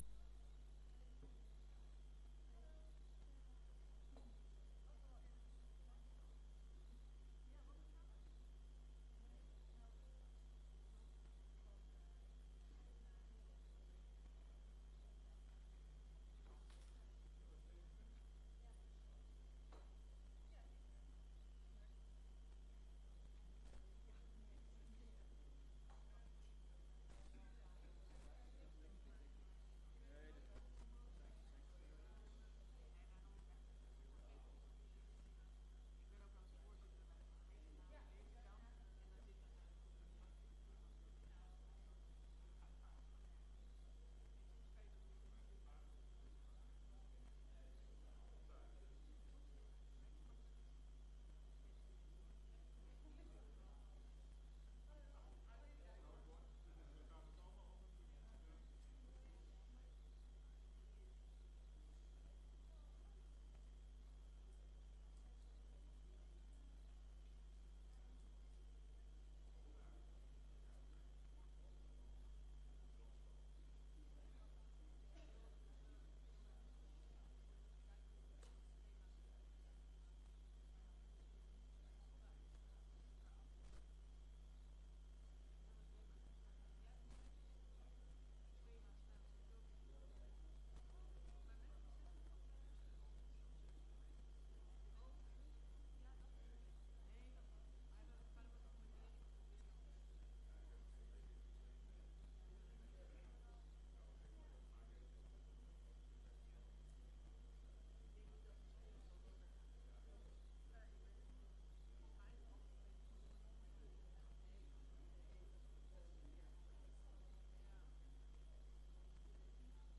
Informatieve raadsvergadering 23 januari 2025 20:00:00, Gemeente Diemen
Locatie: Raadzaal